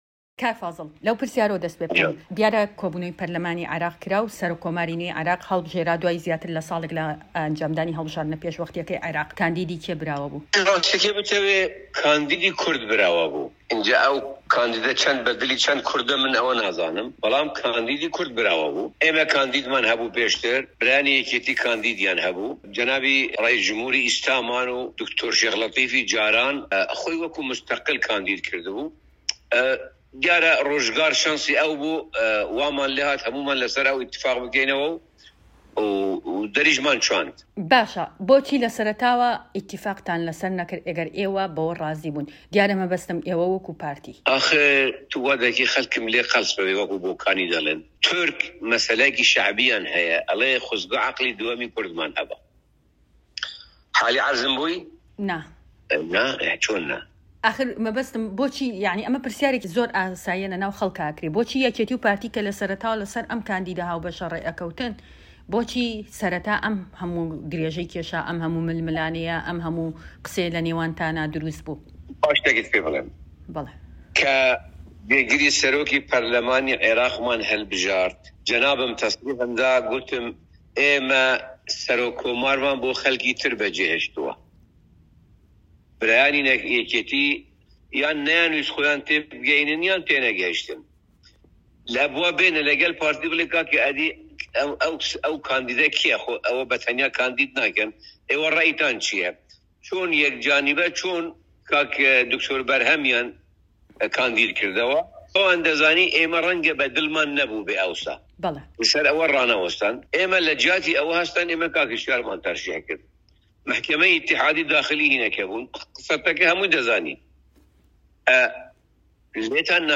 فازڵ میرانی سکرتێری مەکتەبی سیاسی پارتی دیموکراتی کوردستان لەم وتووێژەی بەشی کوردی دەنگی ئەمەریکا، وەڵامی چەند پرسیارێک دەداتەوە لەبارەی پێکهێنانی کابینەی نوێی حکومەتی عێراق و داواکارییەکانی کورد، هەروەها باس لە پەیوەندییەکانی حزبەکەی دەکات لەگەڵ یەکێتی لەدوای هەڵبژاردنی سەرۆک کۆماری عێراق،...